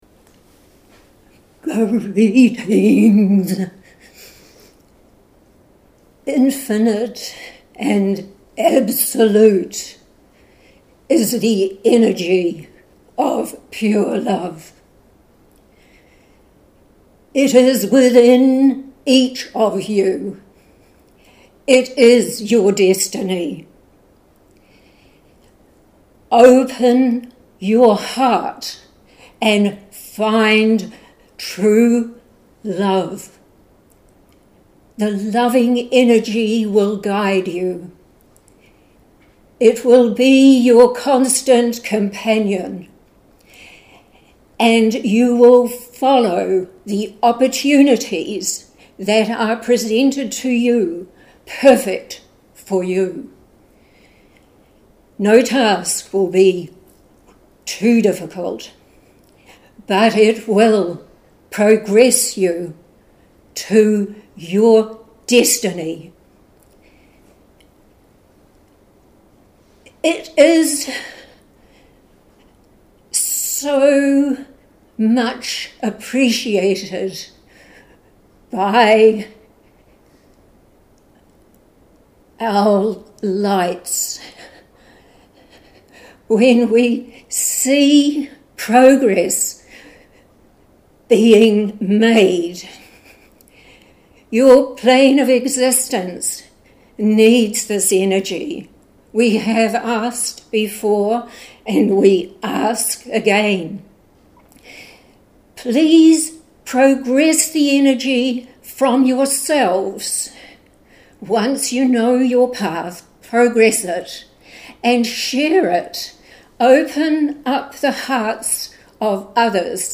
at our weekly meditation group.